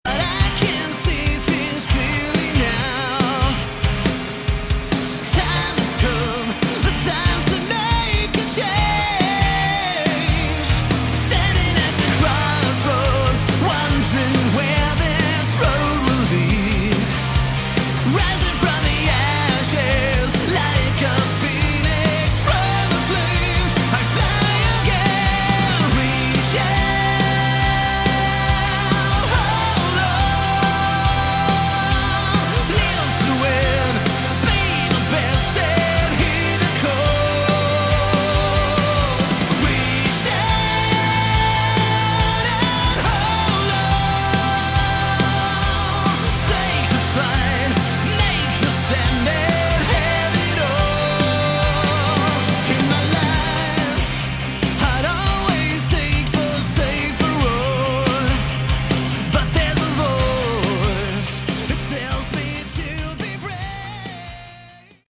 Category: Hard Rock
Vocals
Bass
Drums
Guitar